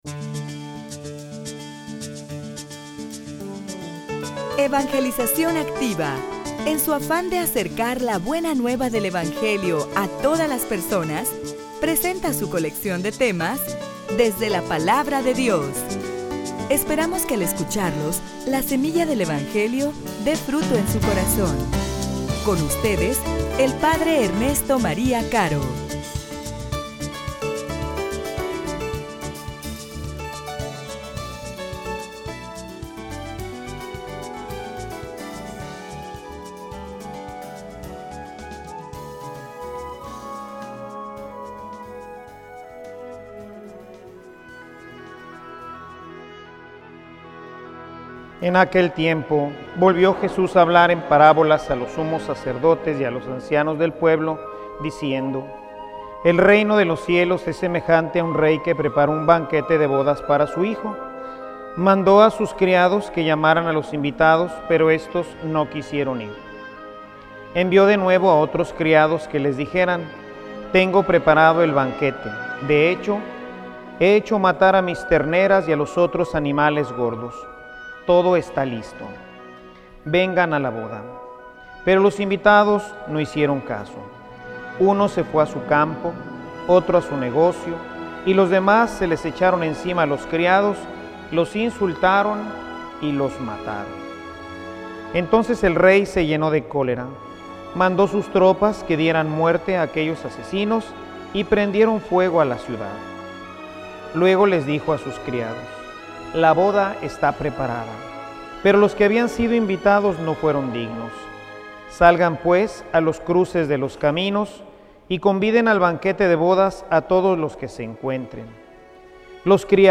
homilia_Donde_esta_tu_traje_de_fiesta.mp3